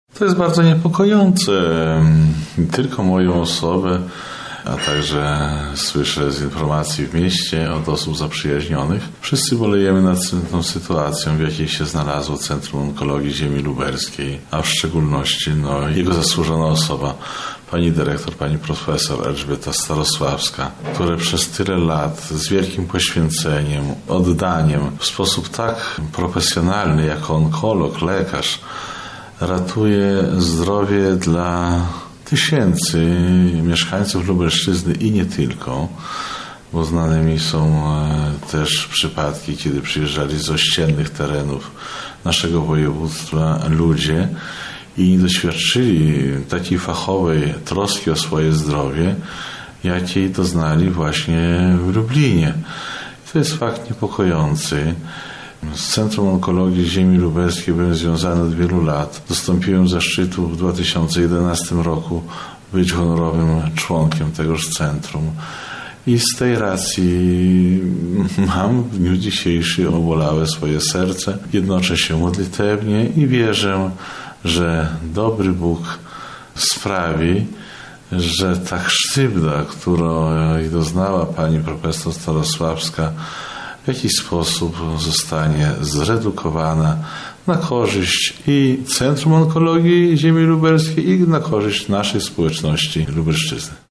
W podobnym tonie wypowiada się prawosławny arcybiskup lubelski i chełmski Abel.